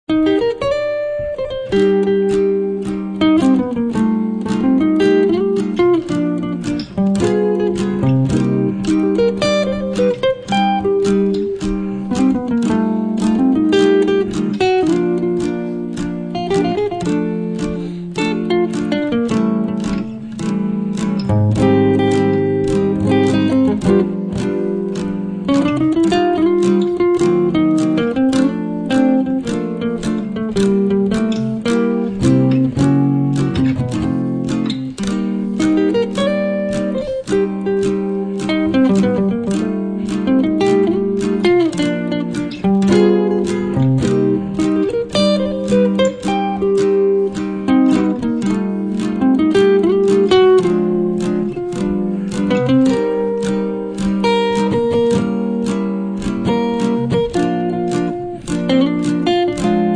chitarra